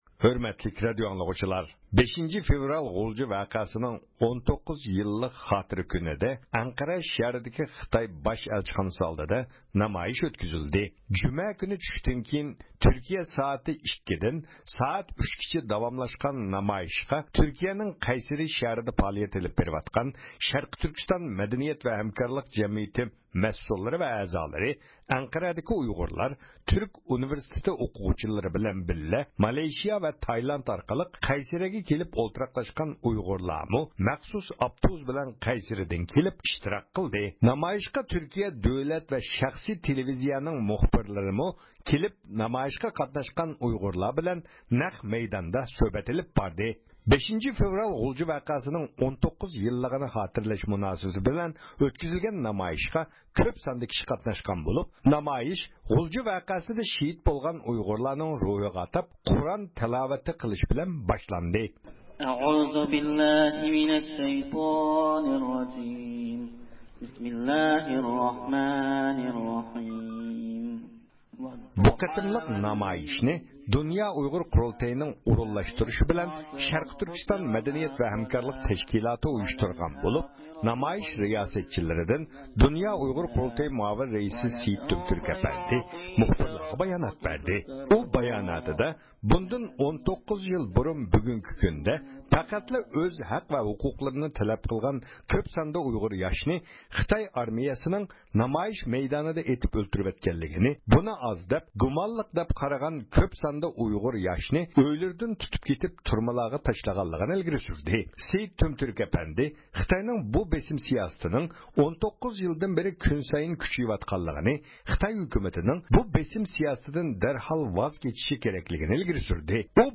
بۇ نۇتۇقلار نامايىشچىلارنىڭ قىزغىن ئالقىش ۋە شۇئارلىرى بىلەن دەستەكلەندى.
ھۆرمەتلىك رادىيو ئاڭلىغۇچىلار، بۇنىڭدىن 20 يىللار ئاۋۋال غۇلجا شەھىرىدە ئۇيغۇر ياشلىرى ئارىسىدا ناركومانلىق يەنى خىروئىنغا ئادەتلىنىش باش كۆتۈرۈپ كۆپلىگەن ياش جانلارغا زامىن بولغان، كۆپلىگەن ئائىلىلەرنى ۋەيران قىلغان.
بۈگۈن ۋاشىنگىتوندىكى خىتاي ئەلچىخانىسى ئالدىغا توپلانغان نامايىشچىلار، مانا شۇ ۋەقەنى خاتىرىلىمەكتە.